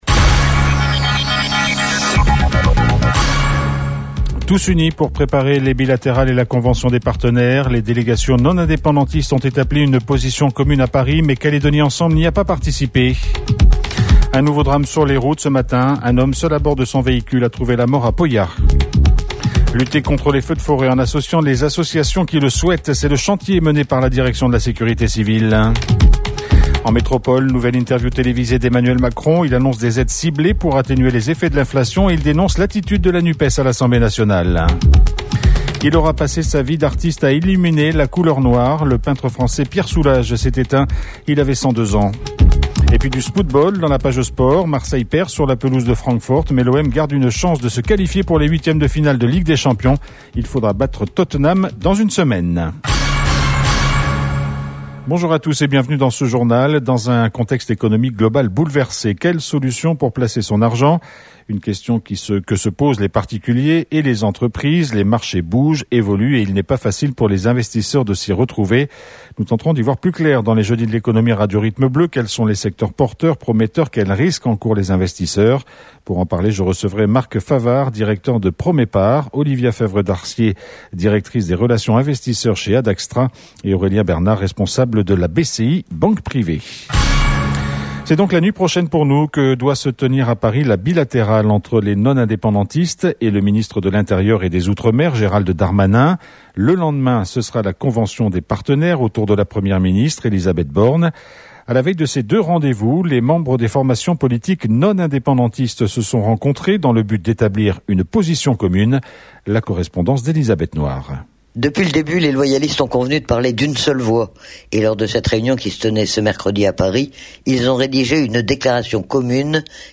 JOURNAL : JEUDI 27/10/22 (MIDI)